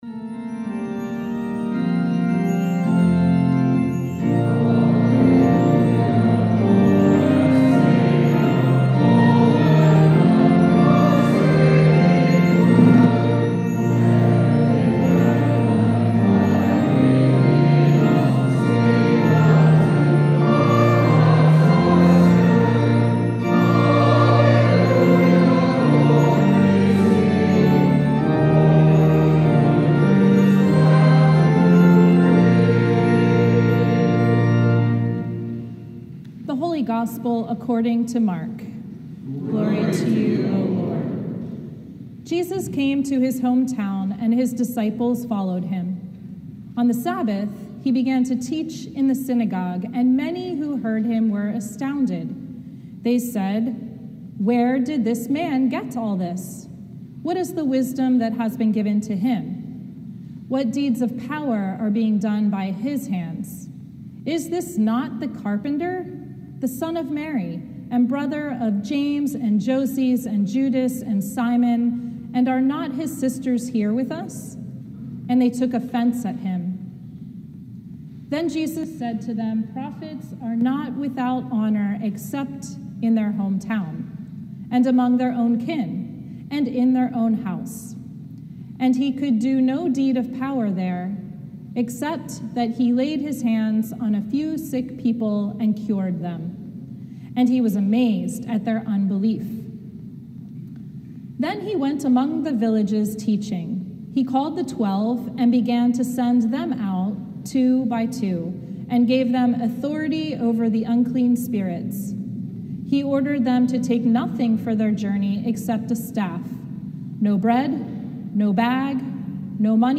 Sermon from the Seventh Sunday After Pentecost